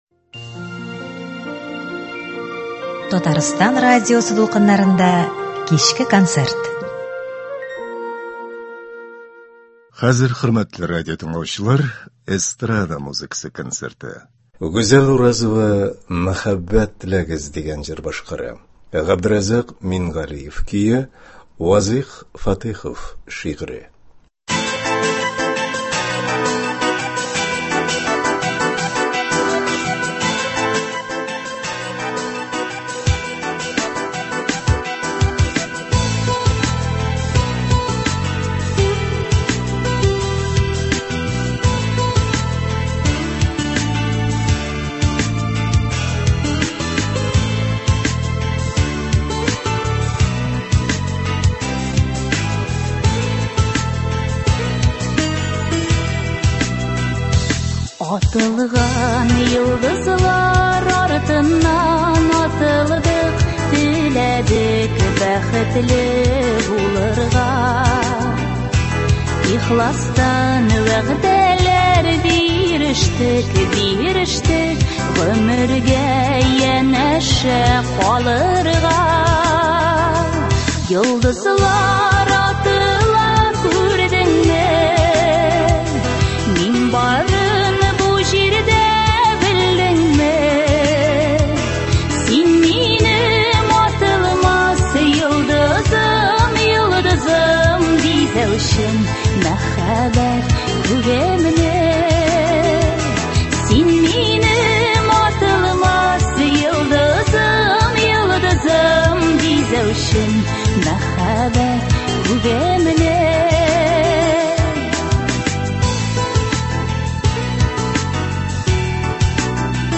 Эстрада музыка концерты.